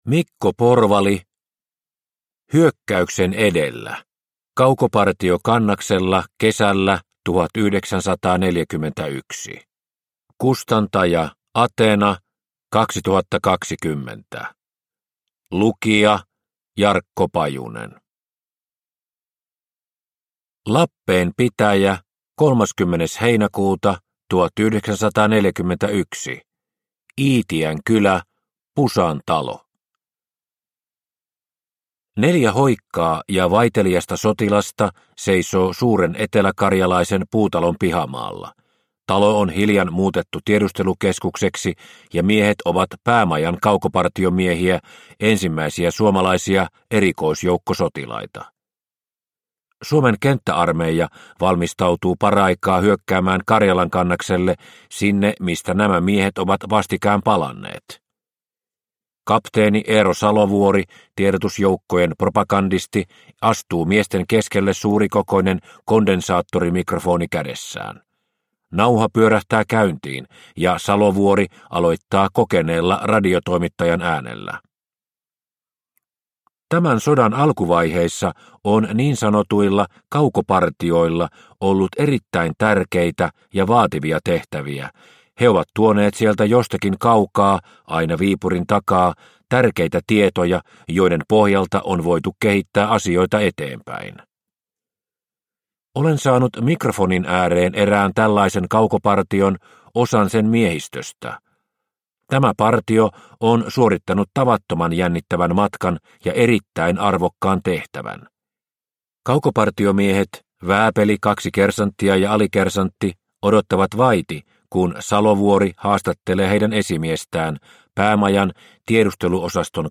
Hyökkäyksen edellä – Ljudbok – Laddas ner